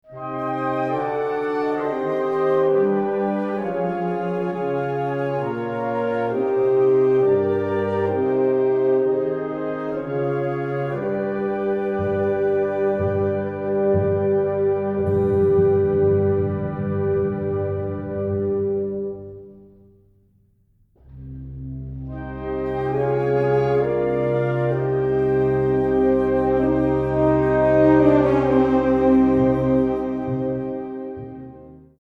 Catégorie Harmonie/Fanfare/Brass-band
Sous-catégorie Chorales, Ballads, Musique lyrique
Instrumentation Ha (orchestre d'harmonie)